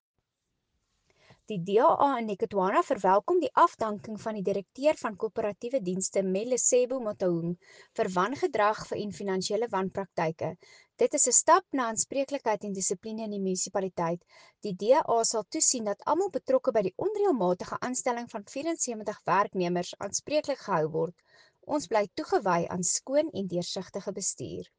Afrikaans soundbite by Cllr Anelia Smit.